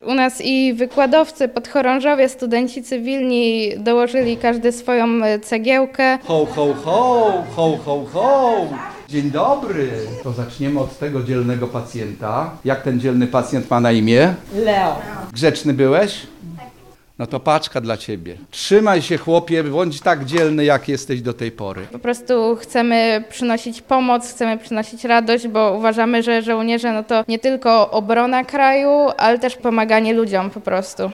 mówi w rozmowie z Radiem Lublin prezes tej studenckiej organizacji